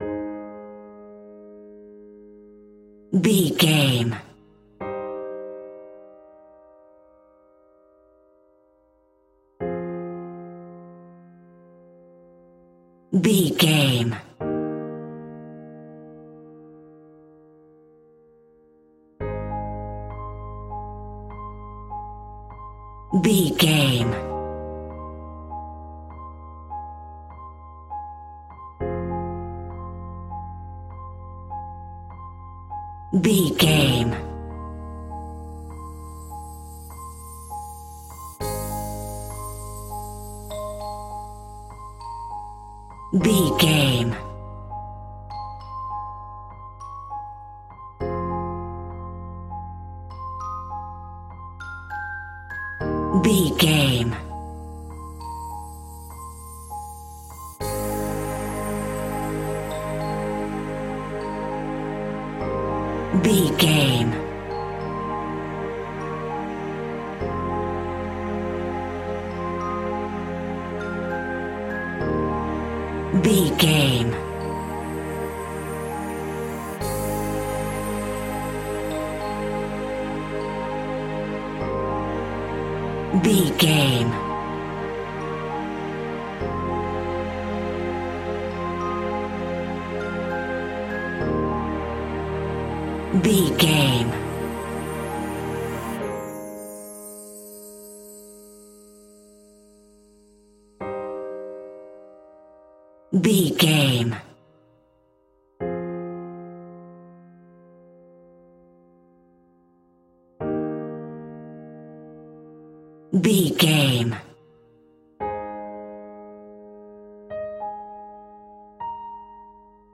royalty free music
Aeolian/Minor
calm
electronic
synthesizer